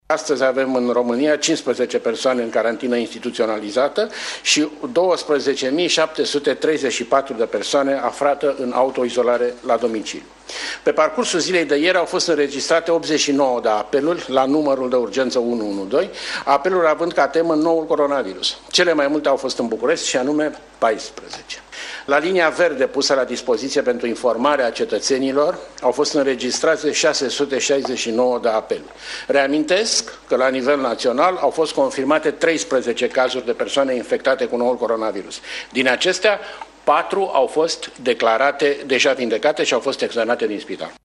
Secretarul de stat în Ministerul Sănătăţii Horaţiu Moldovan a declarat că toţi pacienţii infectaţi cu coronavirus erau duminică în stare bună.